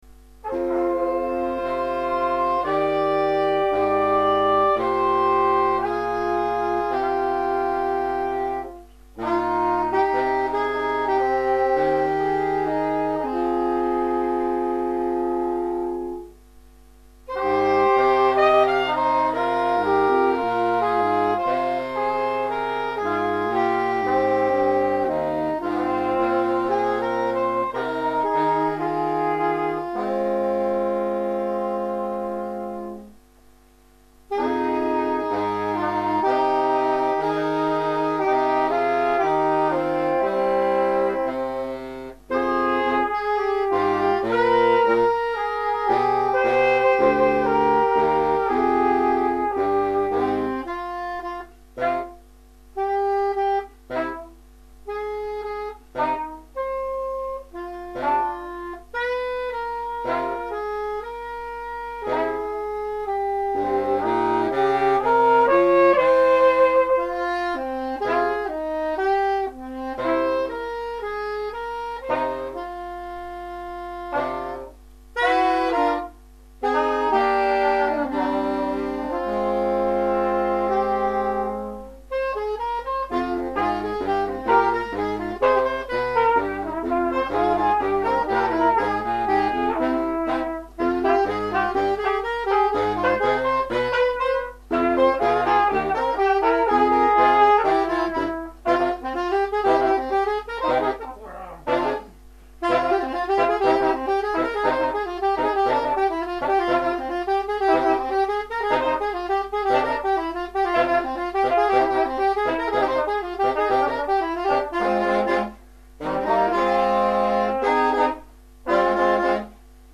ss, bars, tp/flh, !perf
· Genre (Stil): Jazz